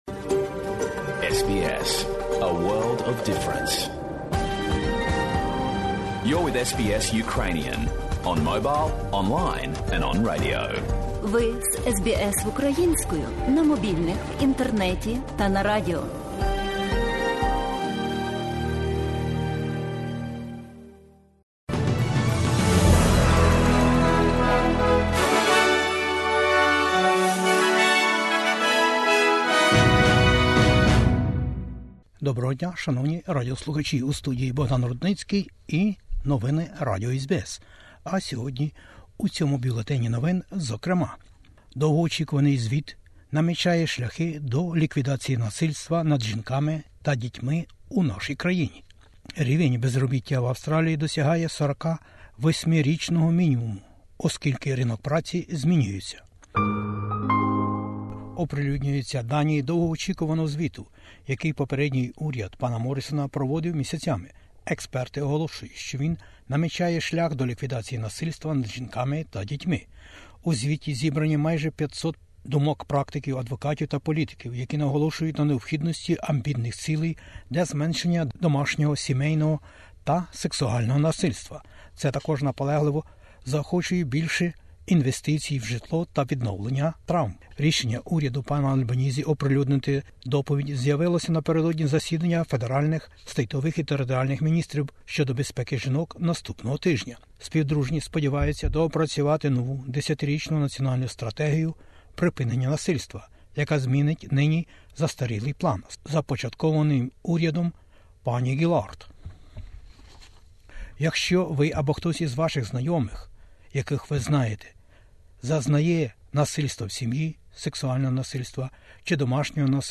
Бюлетень SBS новин українською мовою. Звіт про знущання над жінками, у сім'ях і насильства в Австралії відкриває шлях до поліпшення ситуації. Безробіття в Австралії - найнижче за останні 48 років.